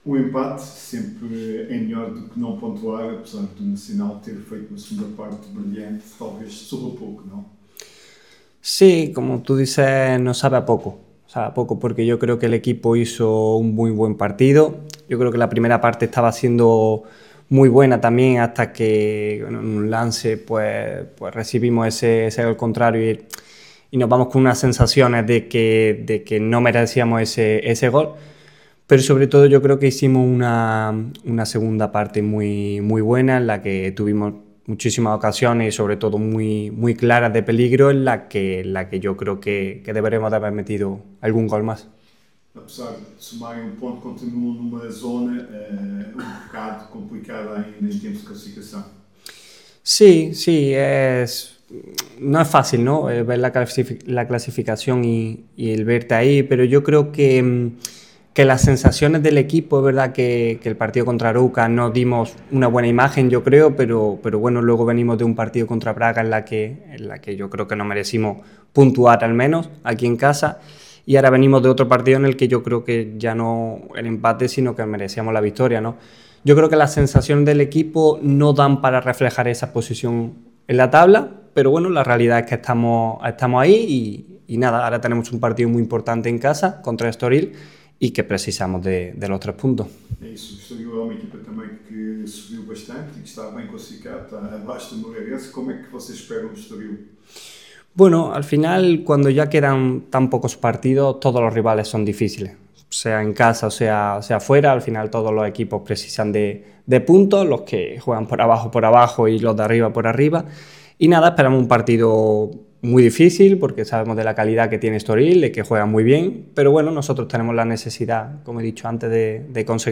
Autor do golo em Moreira de Cónegos, o médio Miguel Baeza passou pela sala de imprensa para falar aos jornalistas.